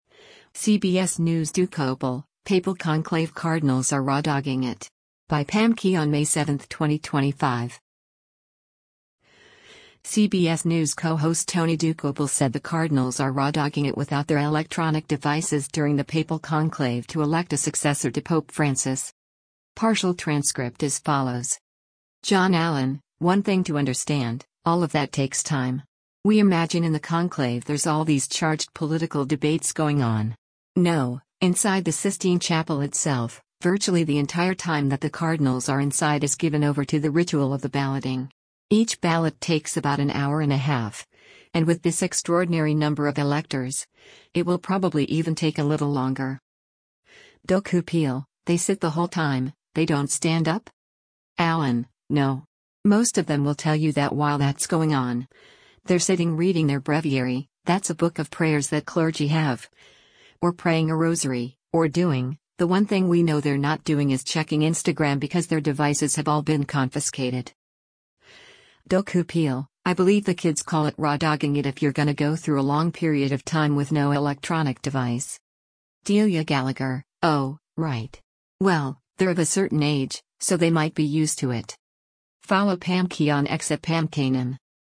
CBS News co-host Tony Dokoupil said the cardinals are raw-dogging it without their electronic devices during the Papal Conclave to elect a successor to Pope Francis.